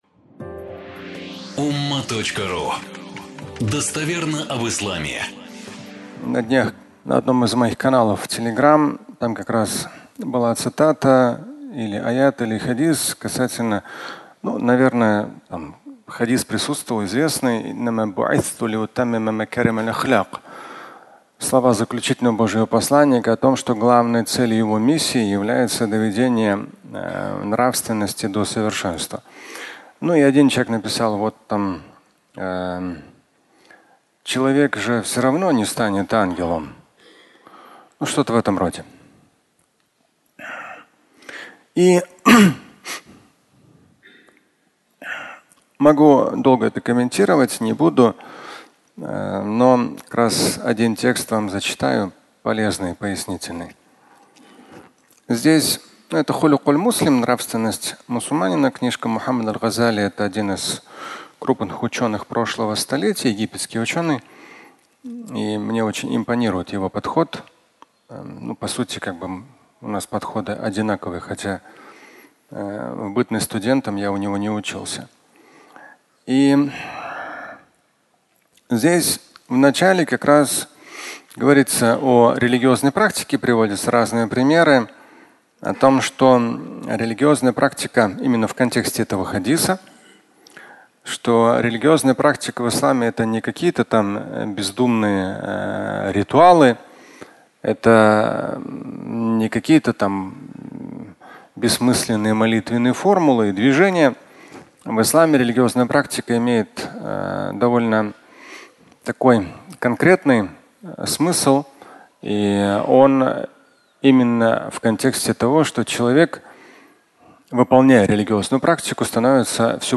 Человек и ангел (аудиолекция)